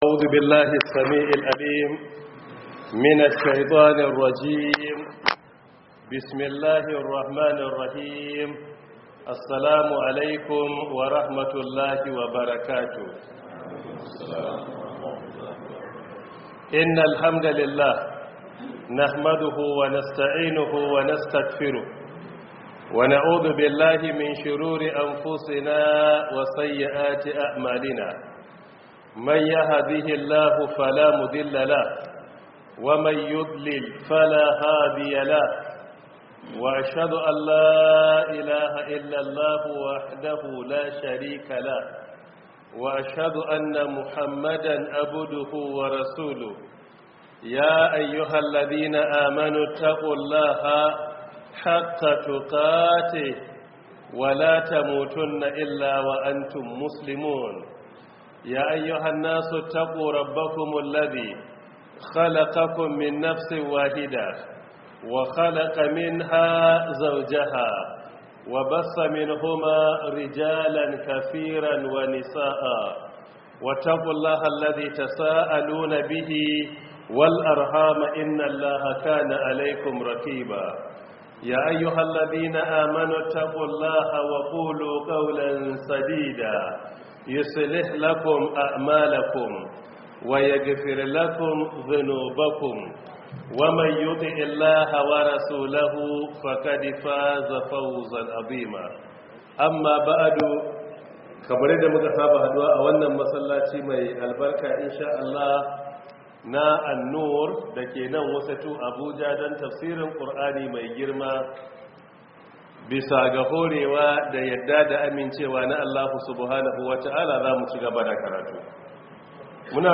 014 Prof Isa Ali Pantami Tafsir 2026